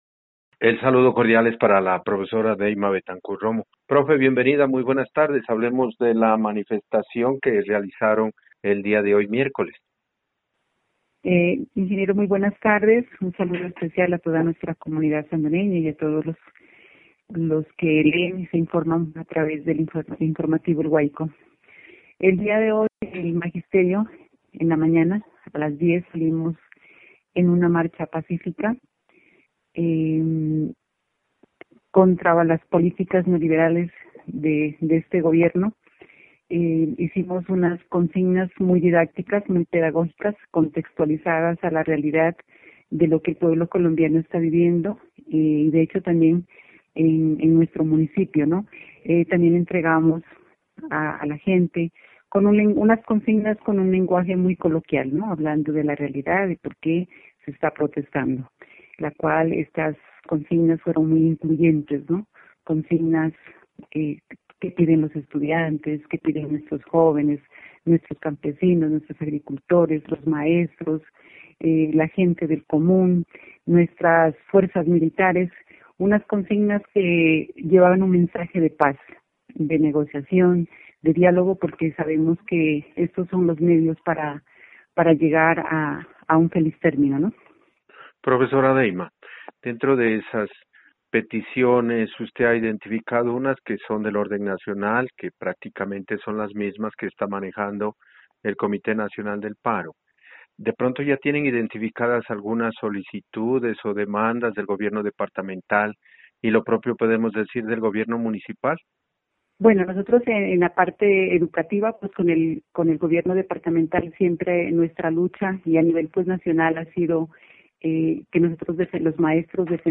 Destacó los logros alcanzados con las manifestaciones realizadas en las diferentes ciudades colombianas, reflejadas en la caída de dos reformas que se iban a tramitar en el Congreso de la República: la reforma tributaria y la reforma a la salud. Entrevista